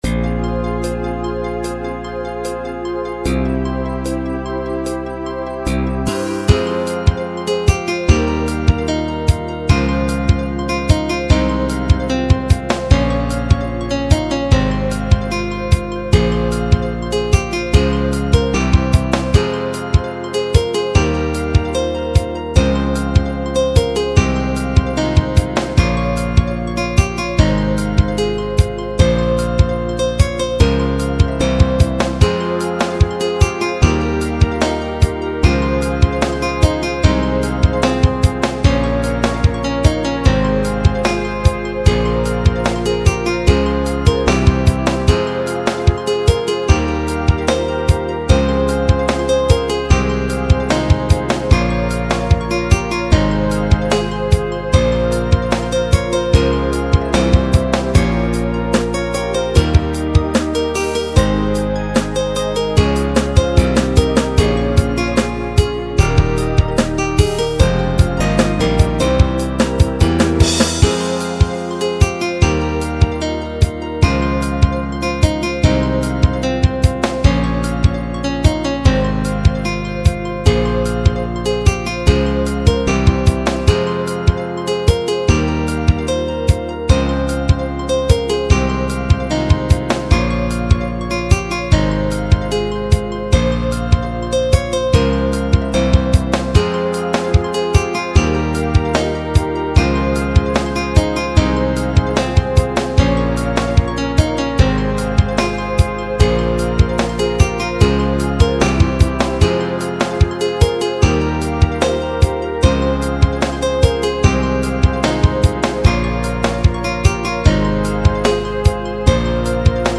・ループ曲です。やや悲しげ。未完成。